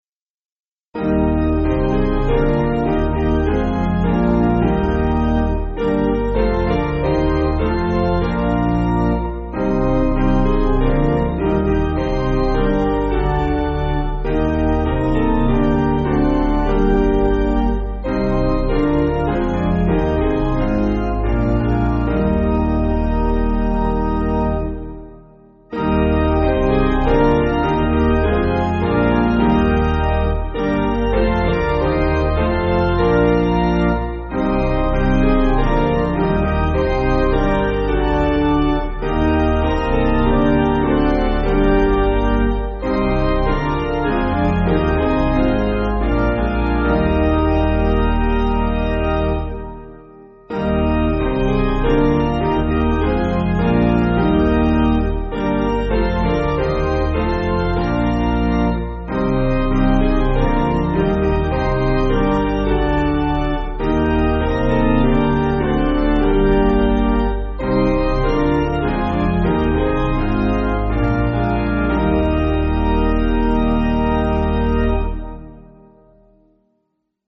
Basic Piano & Organ
(CM)   3/Eb